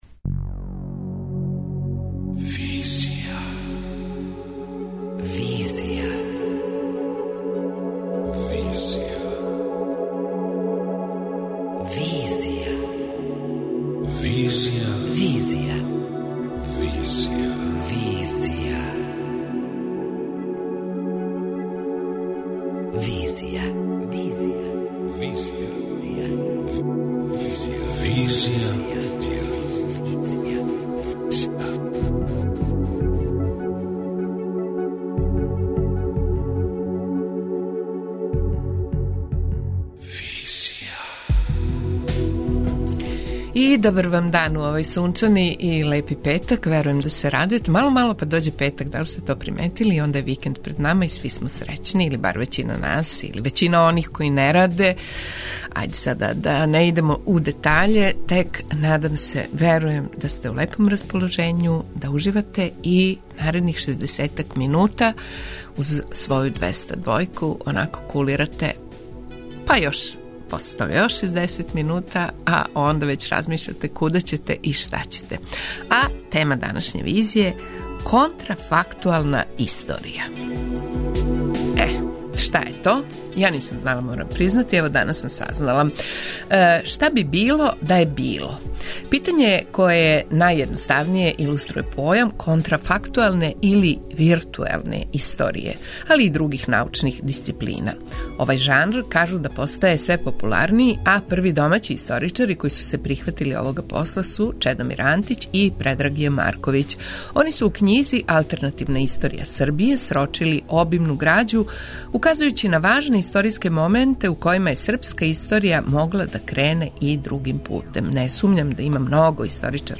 преузми : 27.95 MB Визија Autor: Београд 202 Социо-културолошки магазин, који прати савремене друштвене феномене.